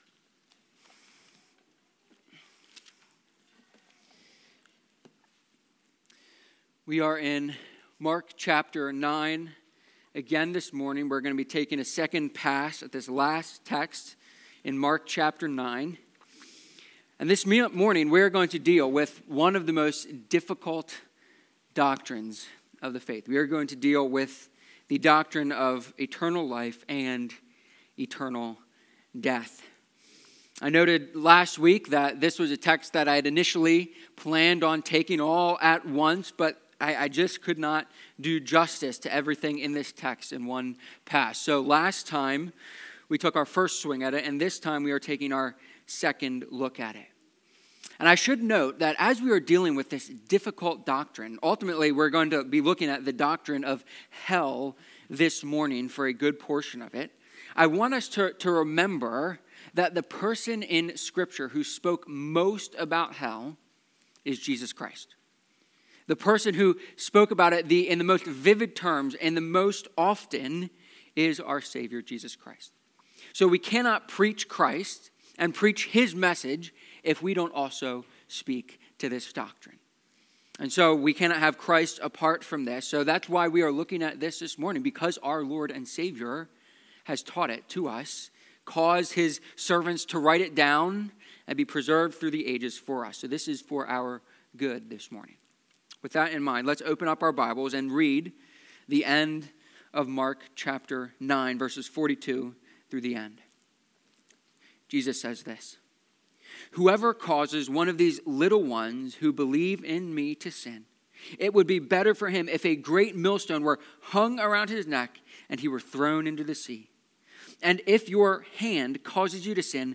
Mark-9-life-and-death-sermon.mp3